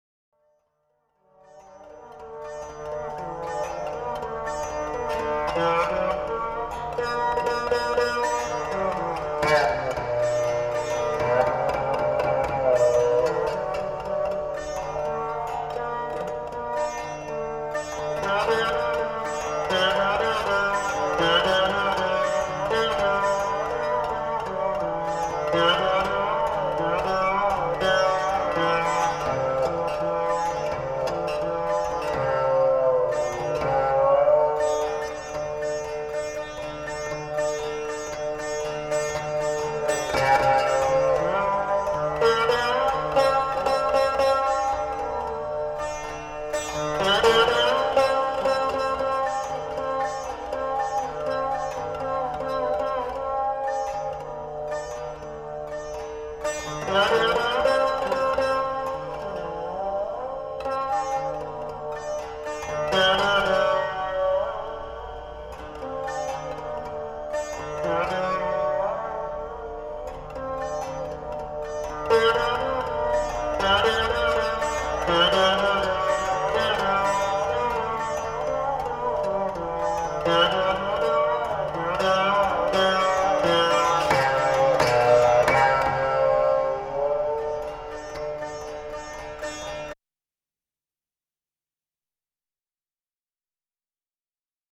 VICHITRA VINA
Indian Classical
alap